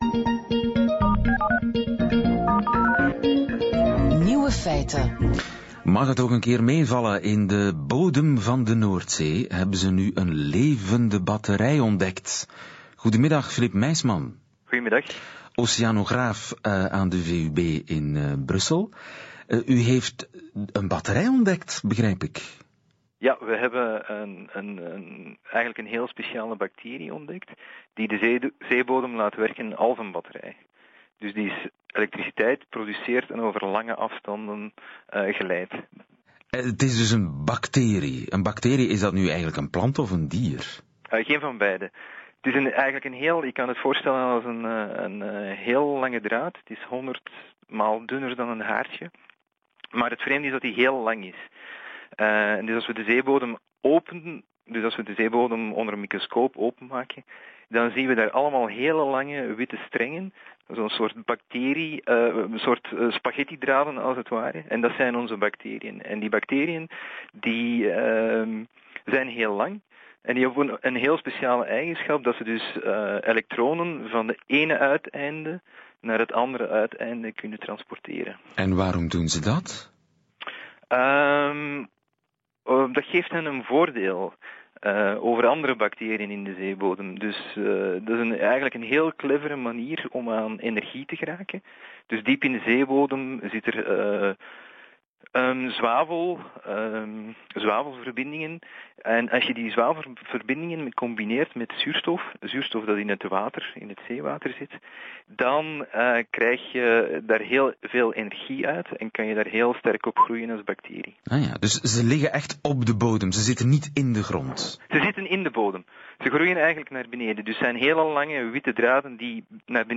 News item on Belgian National Radio 1